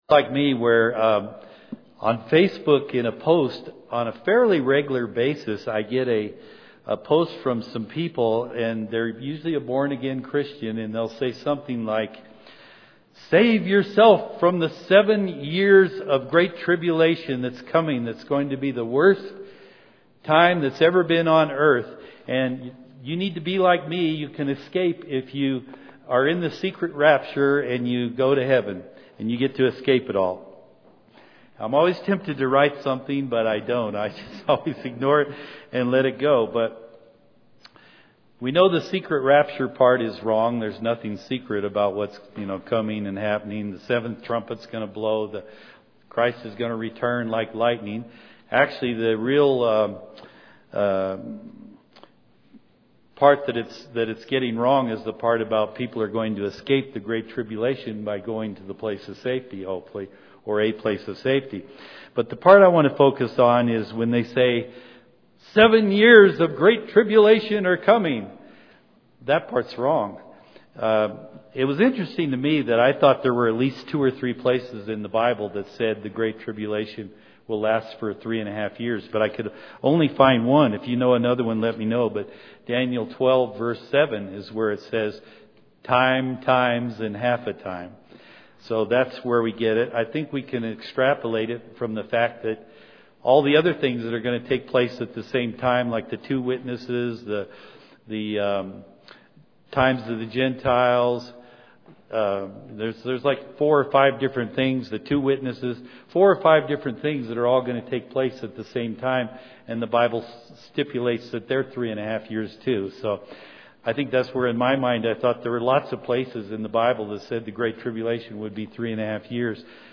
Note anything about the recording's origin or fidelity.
Given in Colorado Springs, CO